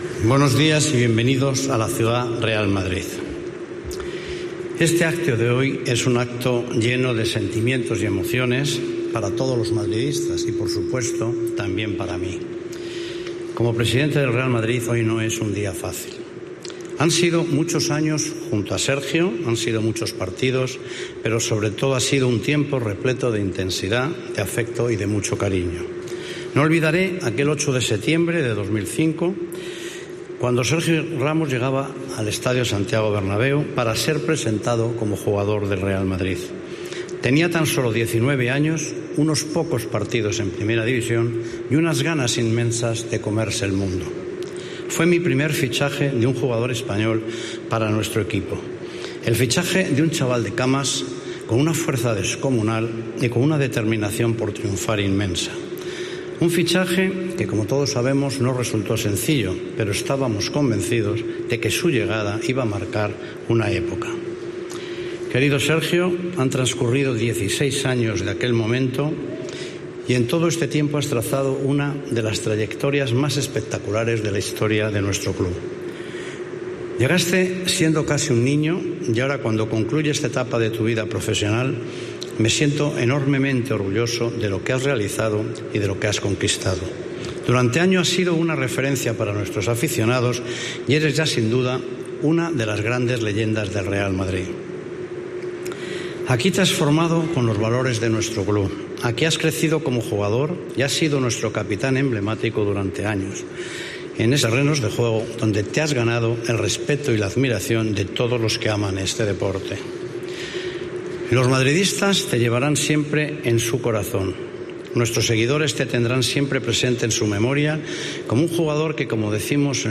El presidente del Real Madrid, Florentino Pérez, dirigó palabras de cariño a Sergio Ramos en su despedida, tras 16 años como jugador madridista: "Gracias por agrandar la historia".
En su adiós, el capitán y futbolista durante 16 temporadas se ha despedido junto al presidente Florentino Pérez desde las 12.30 horas en un acto institucional en la Ciudad Real Madrid.